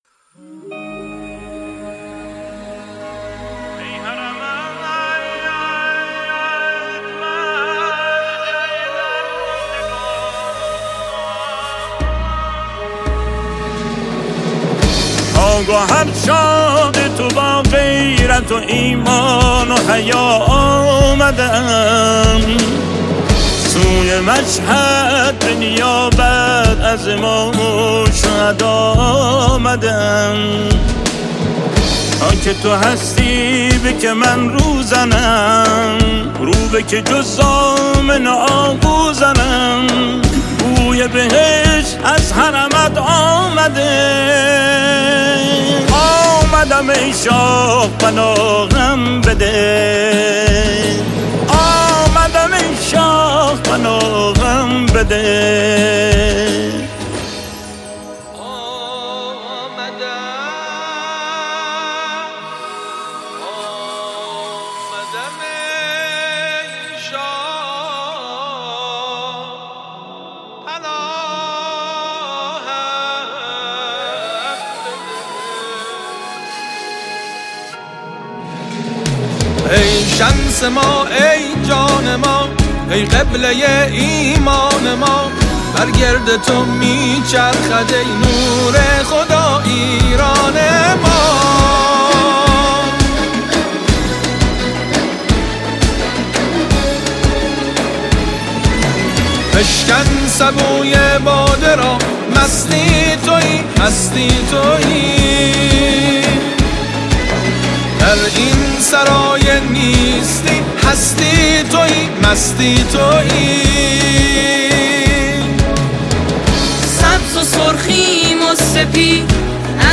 حماسی و ارزشی